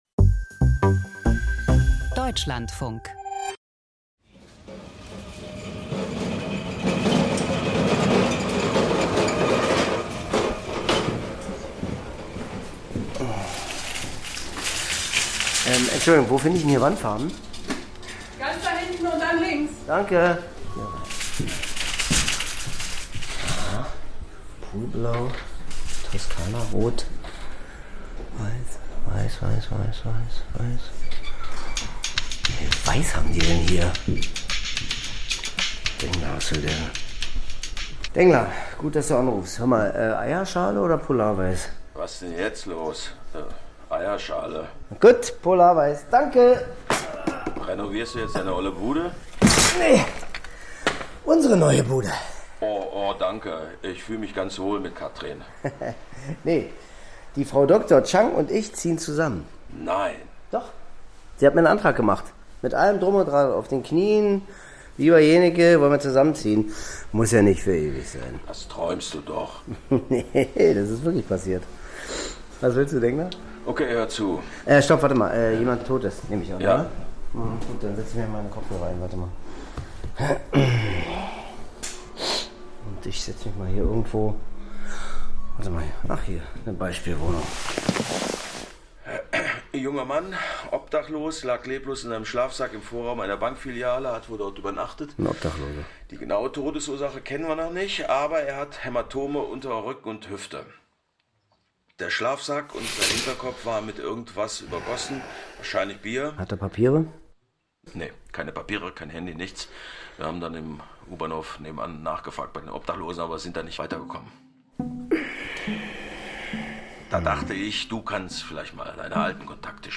Krimi-Hörspiel mit Milan Peschel.